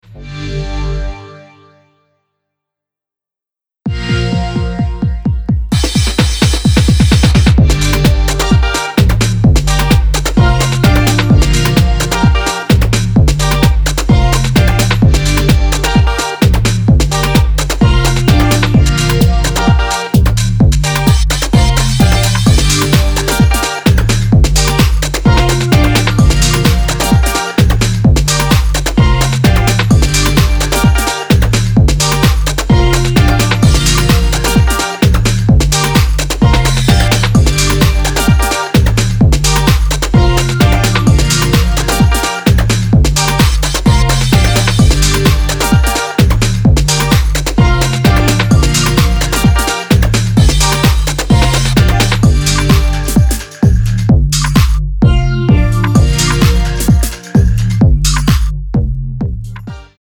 a retro-futuristic take on contemporary house music
House